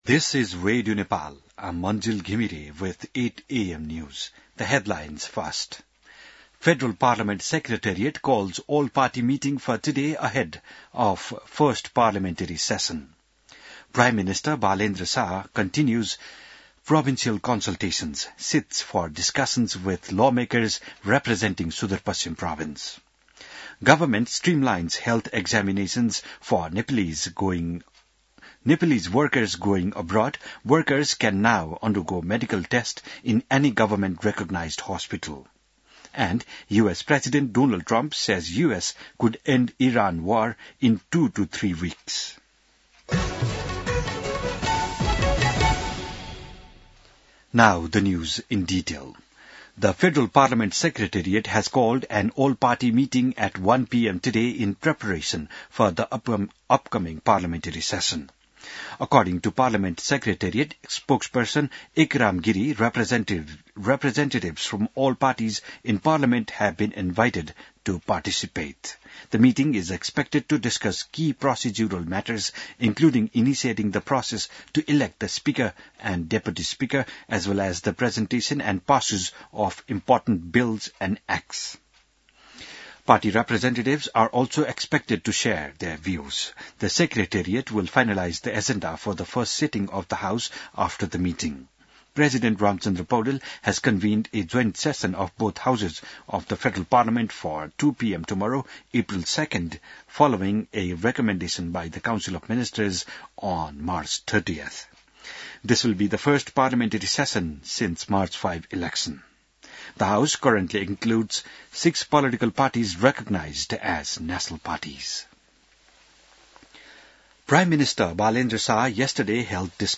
बिहान ८ बजेको अङ्ग्रेजी समाचार : १८ चैत , २०८२